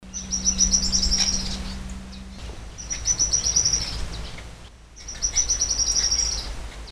Arredio (Cranioleuca pyrrhophia)
Nome em Inglês: Stripe-crowned Spinetail
Localidade ou área protegida: Reserva privada Don Felix y Sacha Juan
Condição: Selvagem
Certeza: Observado, Gravado Vocal